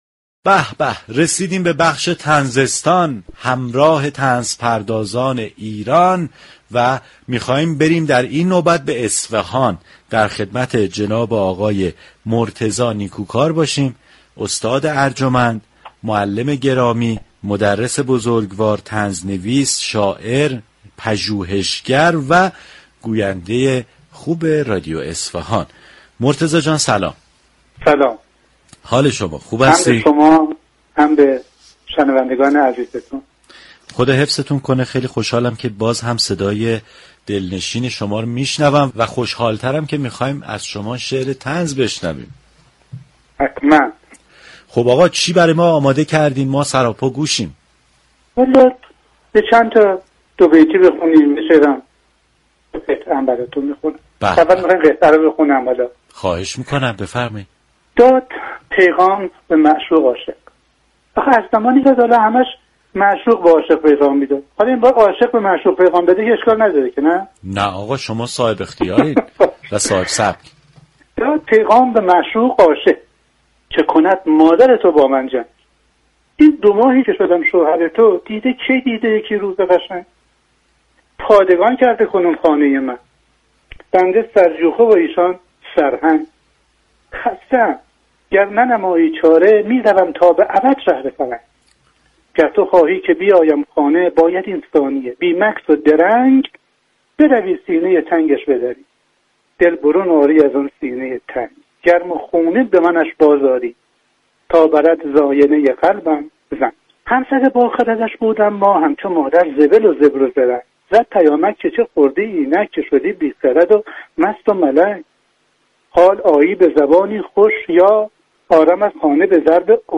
رویداد